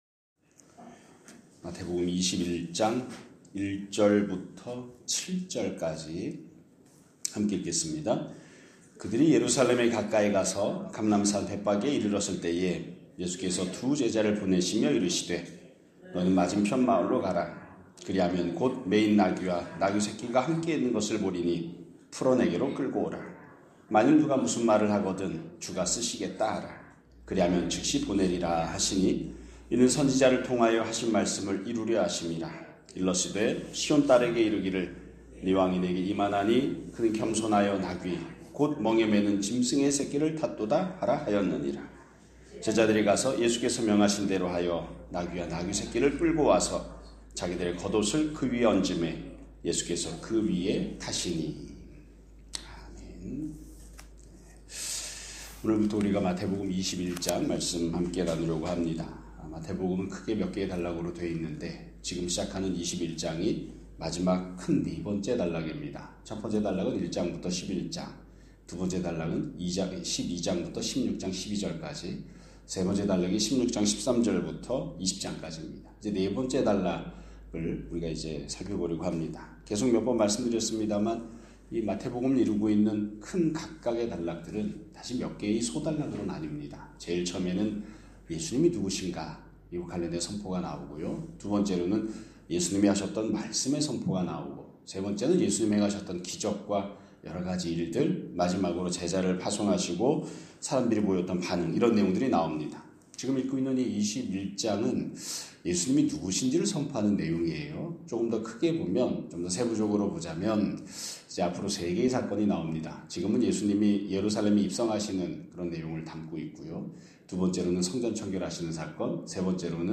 2026년 1월 21일 (수요일) <아침예배> 설교입니다.